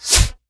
parasitefire.wav